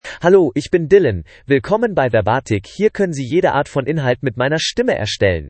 DylanMale German AI voice
Dylan is a male AI voice for German (Germany).
Voice sample
Male
Dylan delivers clear pronunciation with authentic Germany German intonation, making your content sound professionally produced.